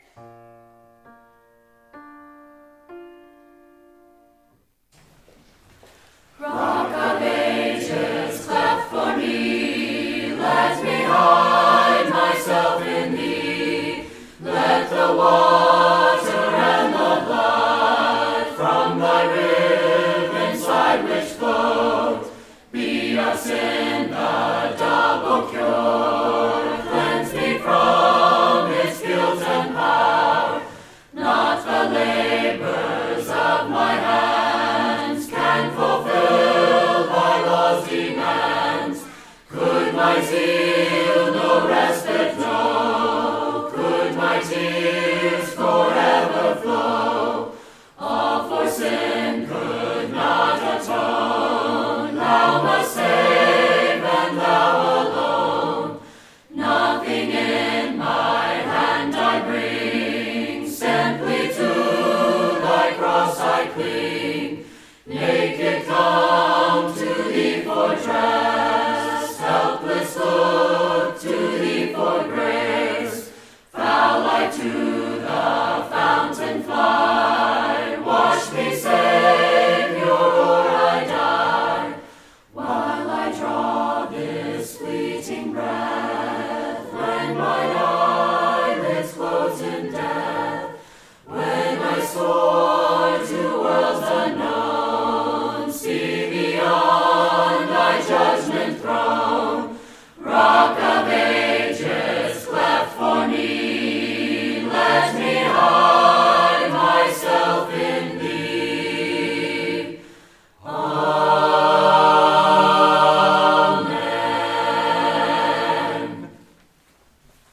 The audio of our last Lord’s Day Call to Worship sung by our Covenant Choir is below.